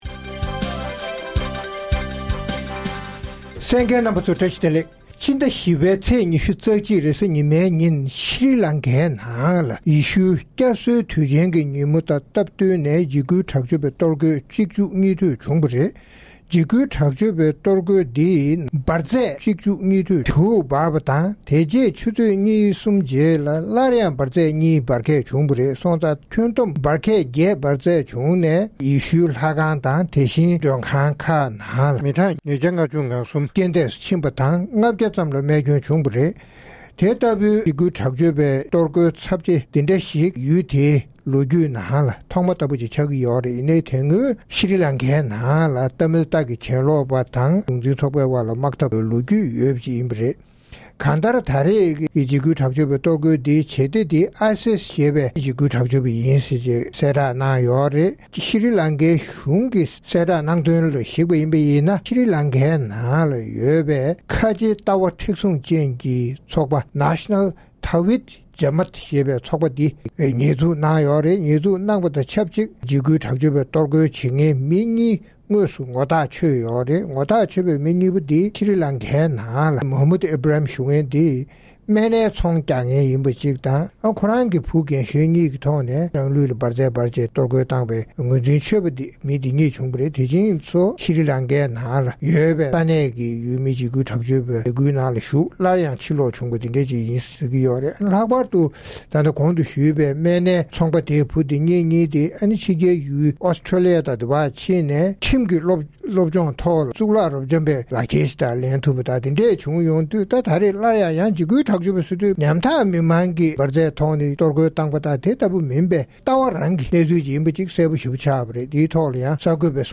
ཛ་དྲག་ཁྲིམས་གཞི་བསྡམས་པ་སོགས་ཀྱི་སྐོར་རྩོམ་སྒྲིག་འགན་འཛིན་རྣམ་པས་བགྲོ་གླེང་གནང་བ་གསན་རོགས་གནང་།།